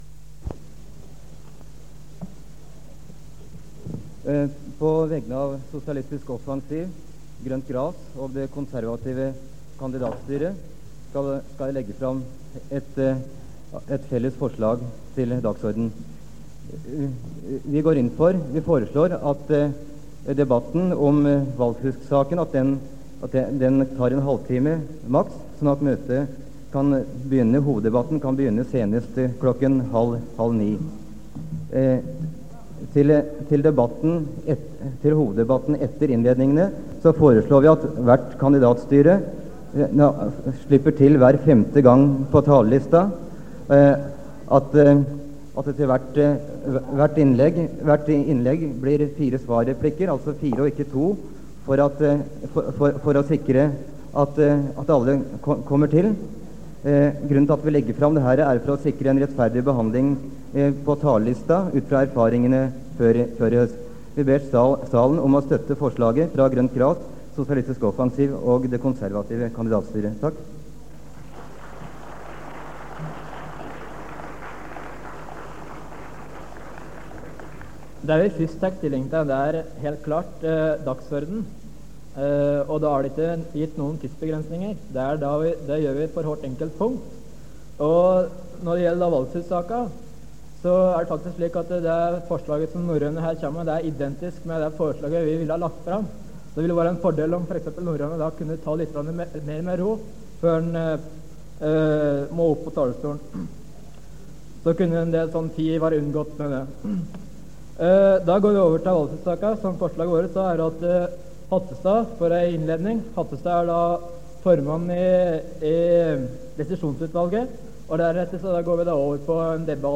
Det Norske Studentersamfund, Generalforsamling, 27.11.1976 (fil 1-3:5)